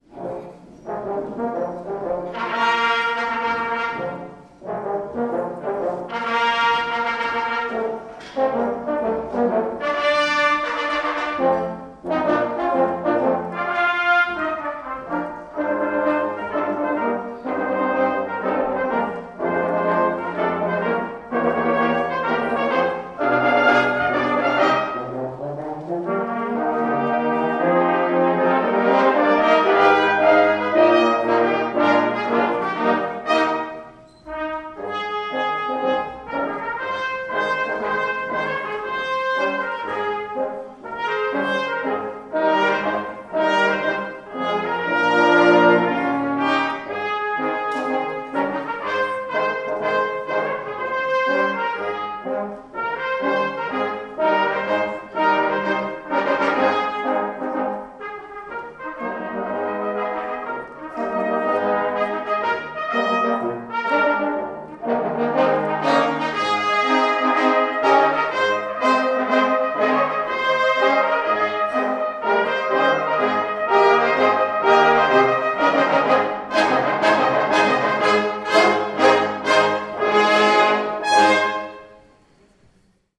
Ein kleiner Ausschnitt aus einem Konzert unseres Bläserensembles
Zu hören ist ein Ausschnitt aus dem „Triumphmarsch“ aus der Oper „Aida“ von Giuseppe Verdi, die übrigens vor genau 150 Jahren am Weihnachtsabend zum ersten Mal aufgeführt wurde. Der Abschnitt mit der bekannten Melodie zum Mitschmettern beginnt übrigens bei 0:33….